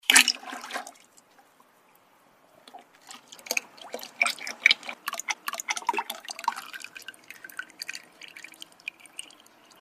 oildrain.mp3